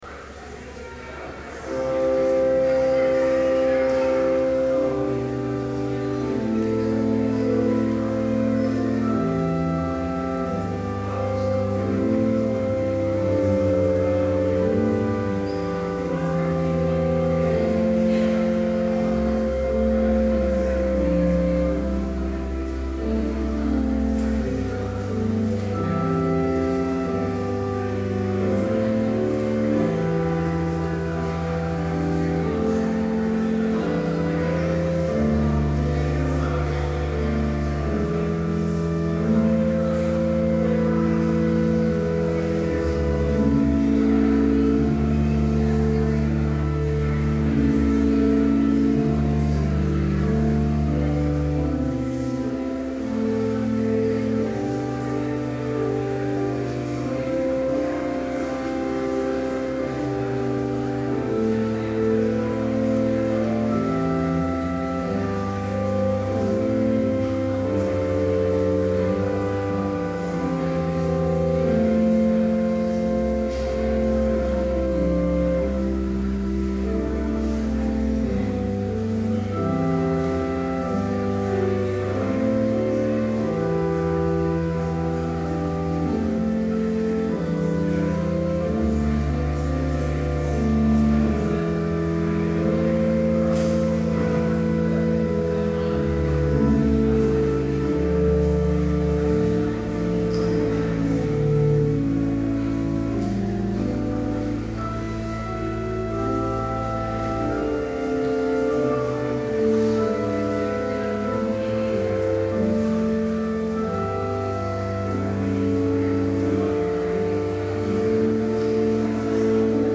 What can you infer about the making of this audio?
Complete service audio for Chapel - March 19, 2012